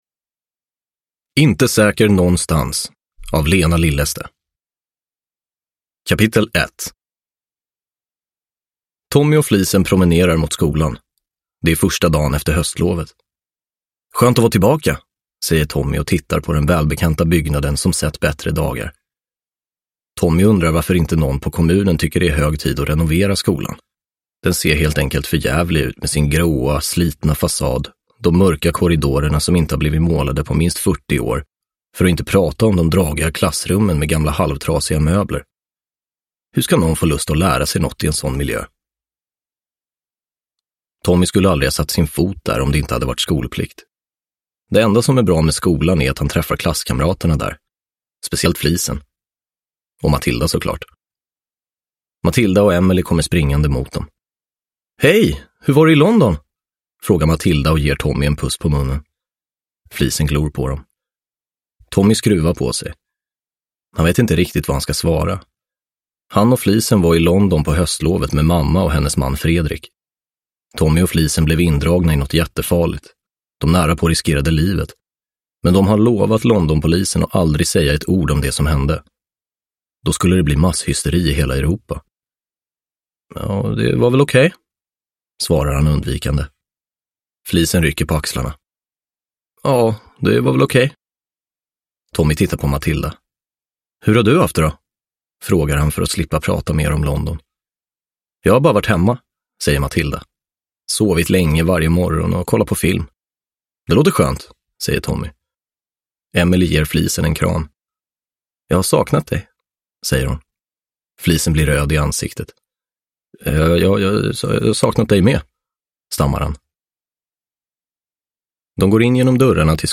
Inte säker någonstans – Ljudbok – Laddas ner
Uppläsare: Anastasios Soulis